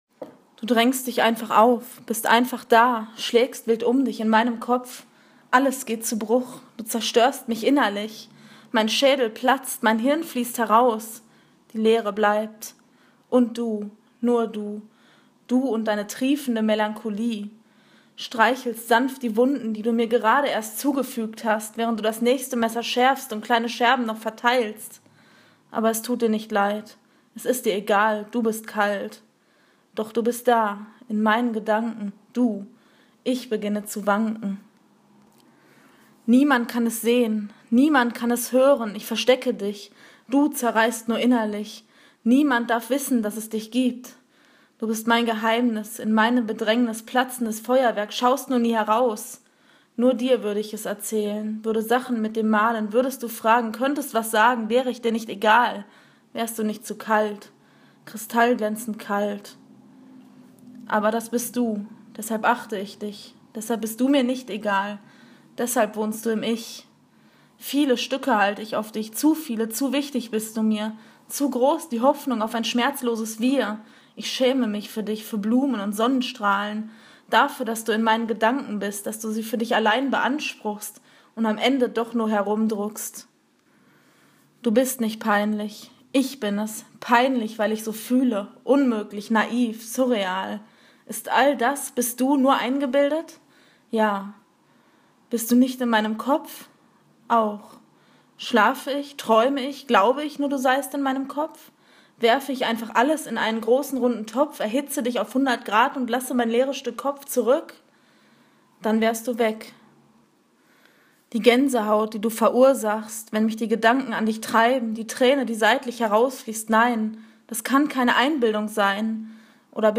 Stimme zum Text: Gelesen – (du)Gedankenblabla.
Hier gibt es dann mal die erste kleine „Lesung“, wenn man so will.
Seid bitte, bitte, ganz doll feste nachsichtig, wenn euch die kleinen Texthänger auffallen. 😛
Schwierig daran war, oder so empfand ich es, den Wechsel der Emotionen.
Ich bin auch noch etwas krank, meine Stimme nach all dem Lesen ist etwas heiser, denke aber, dass es hier echt passend war.